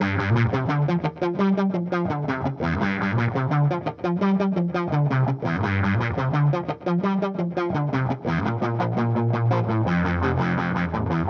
摇滚吉他3 170 Bpm
描述：摇滚吉他循环
Tag: 170 bpm Rock Loops Guitar Electric Loops 1.90 MB wav Key : G